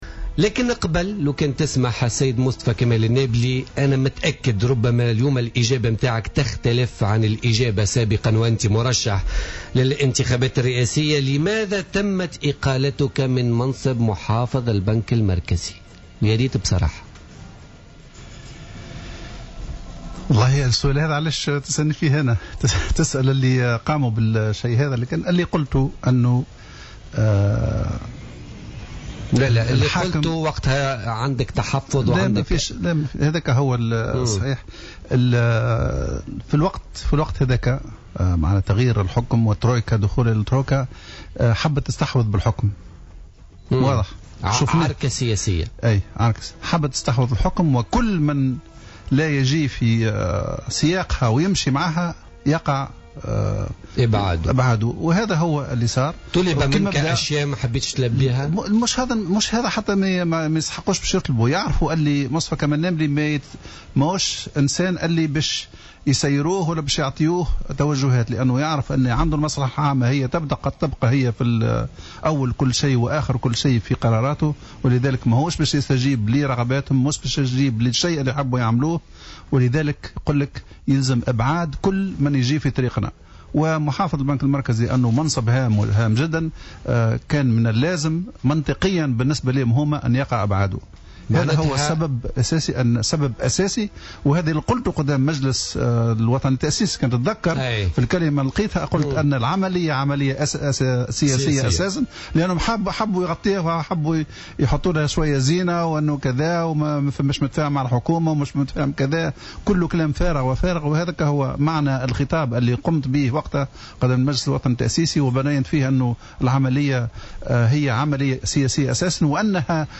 أكد محافظ البنك المركزي السابق مصطفى كمال النابلي ضيف بوليتيكا اليوم الثلاثاء 1 ديسمبر 2015 أن اقالته من منصبه كمحافظ للبنك المركزي كان بسبب تغيير نظام الحكم ودخول الترويكا إلى المشهد موضحا أن الترويكا أرادت أن تستحوذ على الحكم وكل من كان يخالفها الرأي كان جزاؤه الإقصاء والإستبعاد.